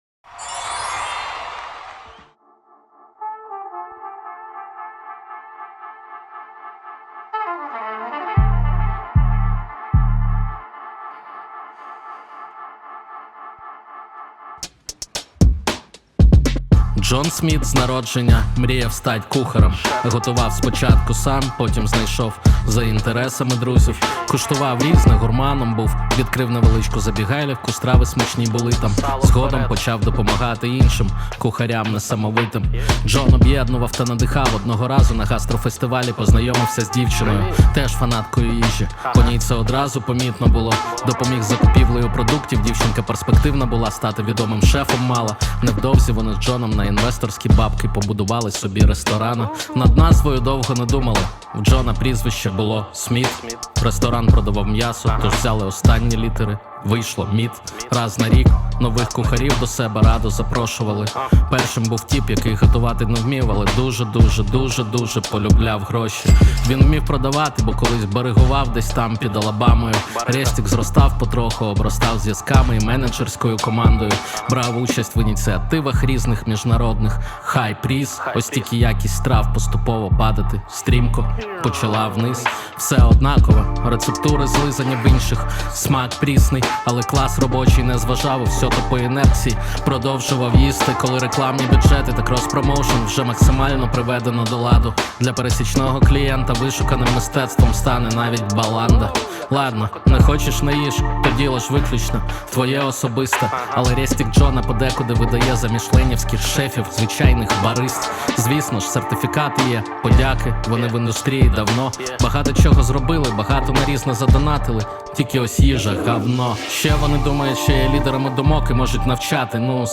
• Жанр: Rap